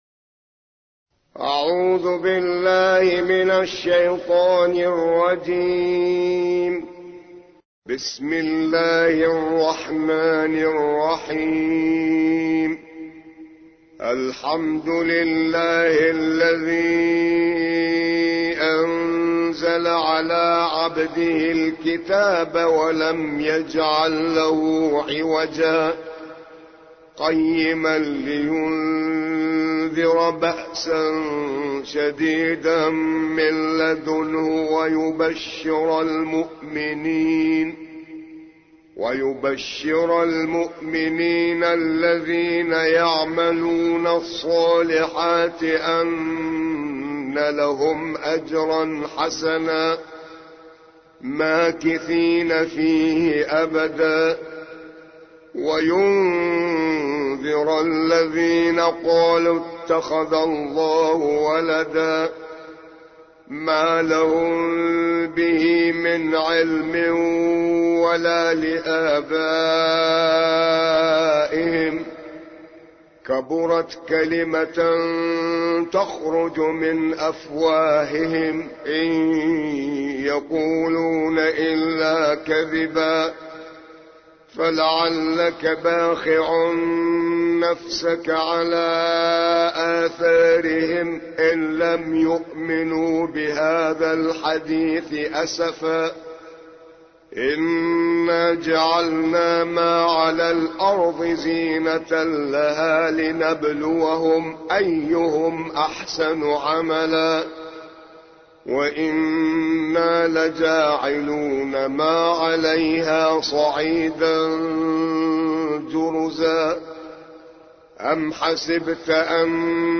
18. سورة الكهف / القارئ